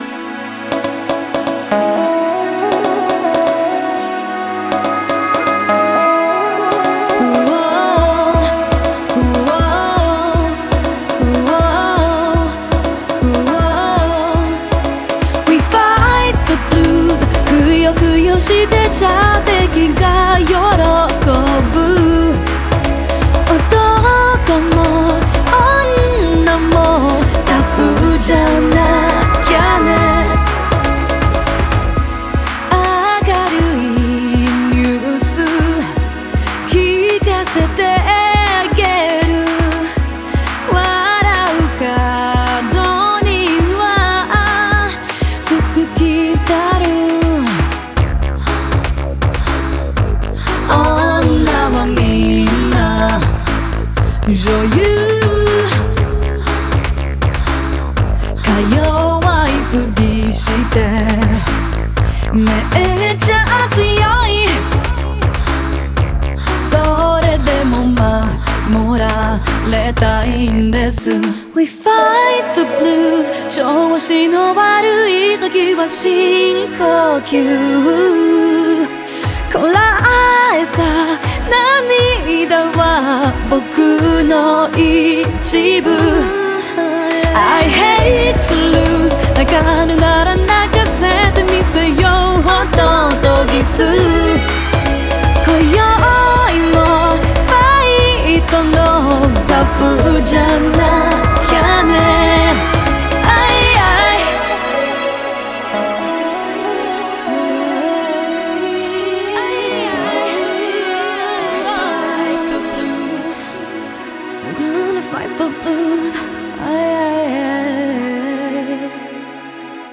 BPM120
Audio QualityPerfect (High Quality)
It's a nice uplifting feel-good track.